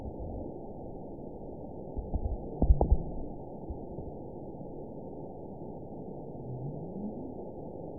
event 920288 date 03/13/24 time 00:32:03 GMT (1 year, 1 month ago) score 9.44 location TSS-AB04 detected by nrw target species NRW annotations +NRW Spectrogram: Frequency (kHz) vs. Time (s) audio not available .wav